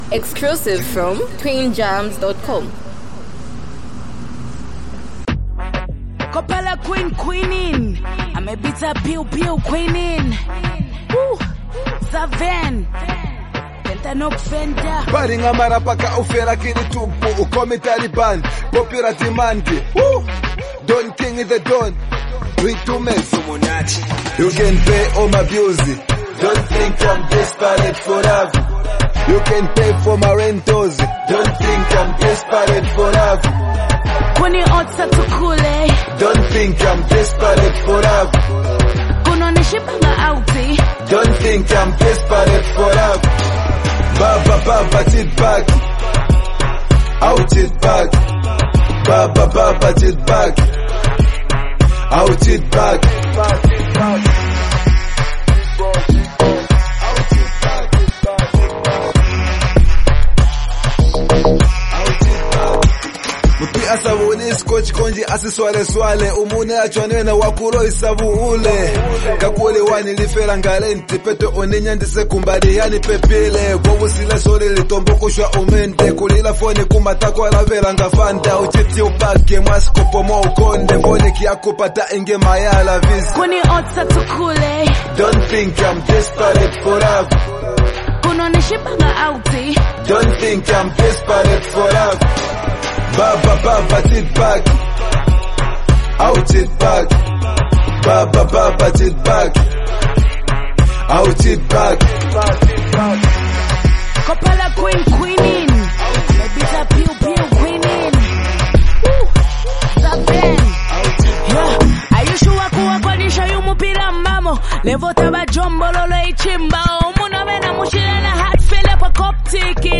a bold, emotionally charged song
a powerful female perspective